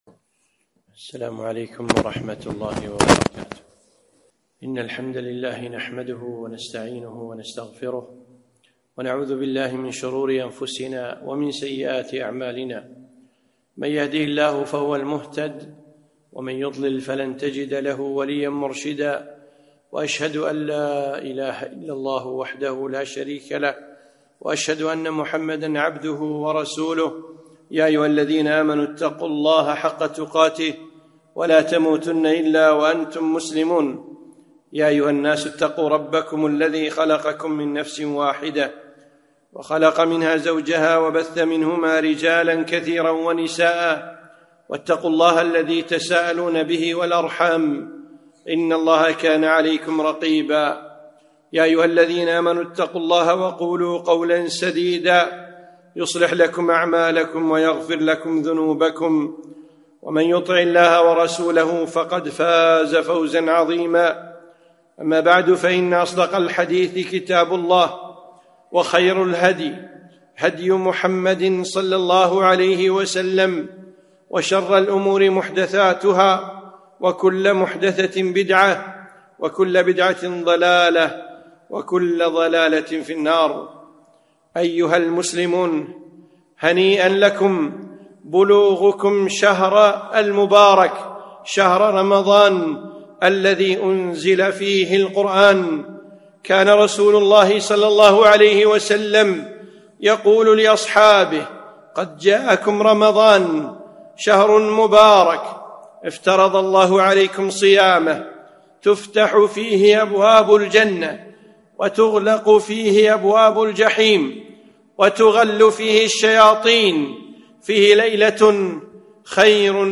خطبة - ( وأقبل رمضان )